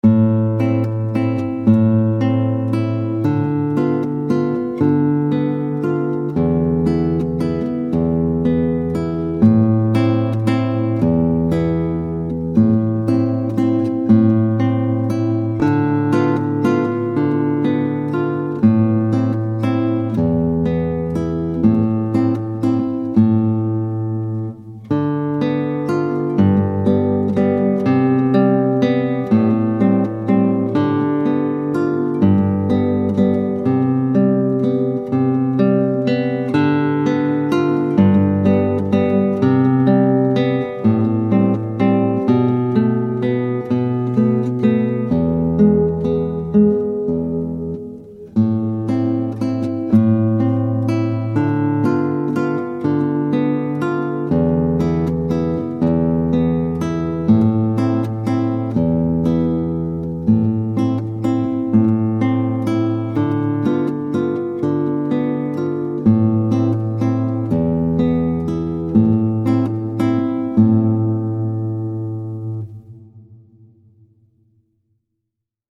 Gitarre Solo
• Thema: tirando-Anschlag
• Instrumenten: Gitarre Solo